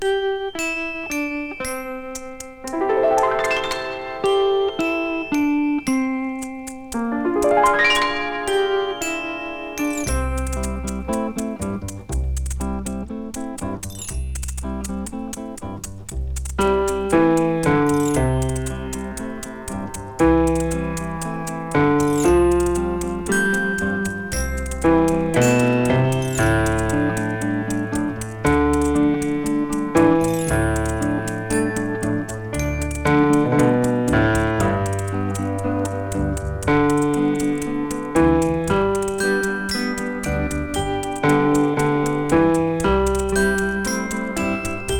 ステレオ録音技術の躍進もあり、スピーカーの片方ずつにピアノを配置。
味わいと深みある音が素晴らしい作品です。